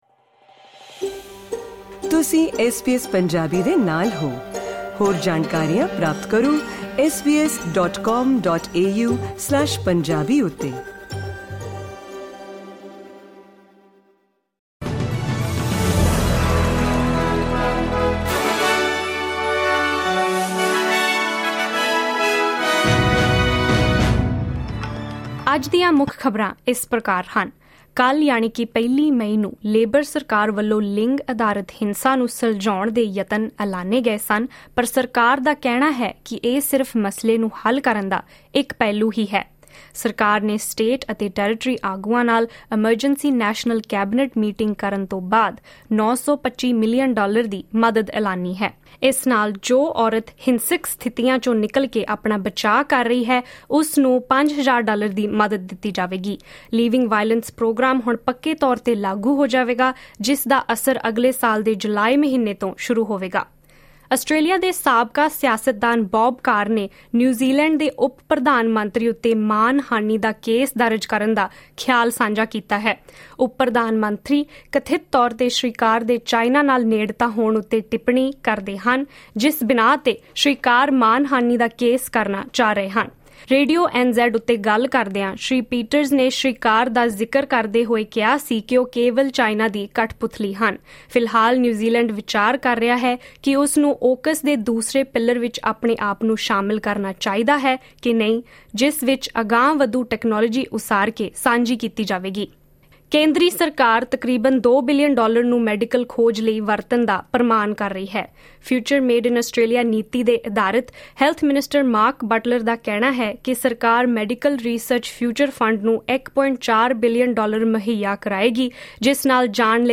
ਐਸ ਬੀ ਐਸ ਪੰਜਾਬੀ ਤੋਂ ਆਸਟ੍ਰੇਲੀਆ ਦੀਆਂ ਮੁੱਖ ਖ਼ਬਰਾਂ: 2 ਮਈ, 2024